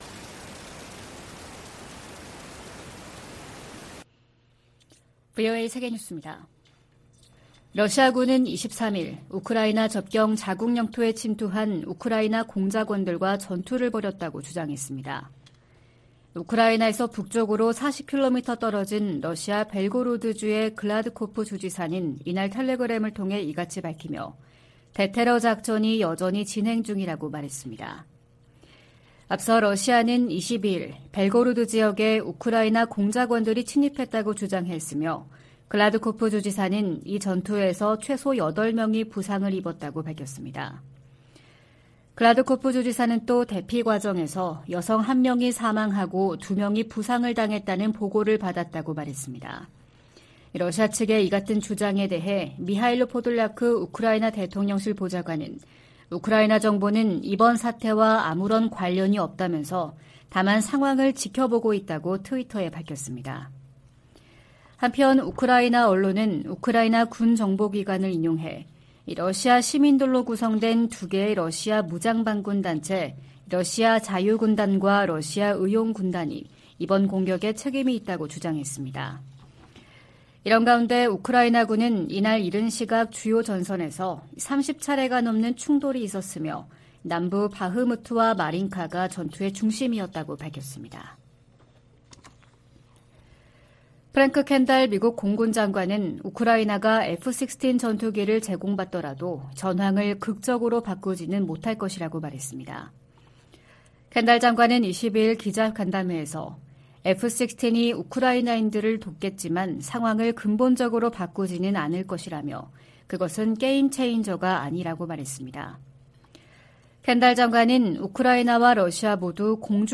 VOA 한국어 '출발 뉴스 쇼', 2023년 5월 24일 방송입니다. 조 바이든 미국 대통령은 일본 히로시마 G7 정상회의로 미한일 3자 협력이 새 차원으로 격상됐다고 말했습니다. G7을 계기로 미한일 공조가 강화되면서 북한, 중국, 러시아의 외교, 안보, 경제의 밀착이 구체화 될 것이라고 전문가들이 내다보고 있습니다. 한국과 유럽연합(EU) 정상이 북한의 도발 행위를 강력히 규탄하며 비핵화 대화에 복귀할 것을 촉구했습니다.